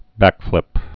(băkflĭp)